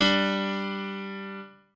piano2_18.ogg